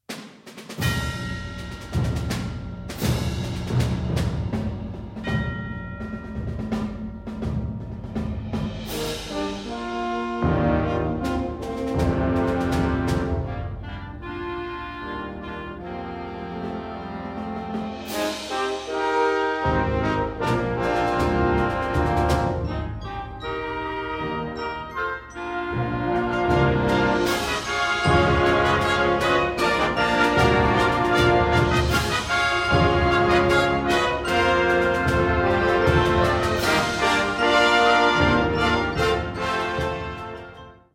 Categorie Harmonie/Fanfare/Brass-orkest
Subcategorie Patriottische muziek
Bezetting Ha (harmonieorkest)